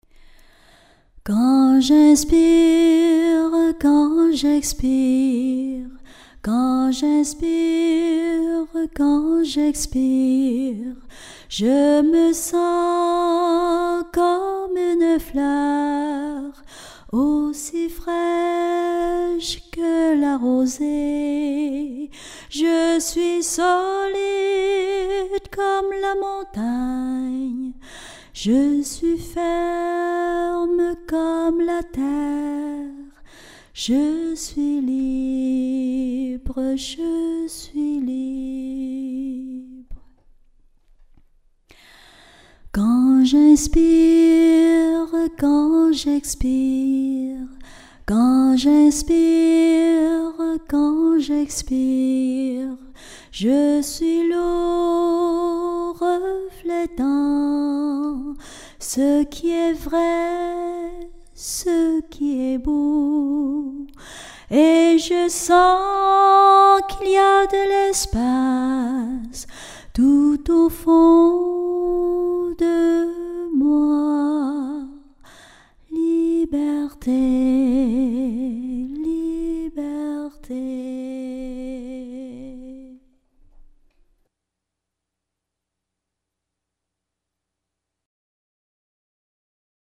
Chanson de pratique classique